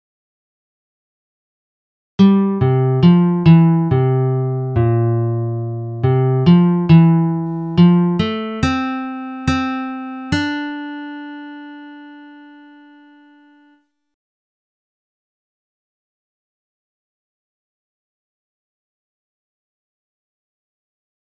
Key written in: C Major
Type: Other male
Each recording below is single part only.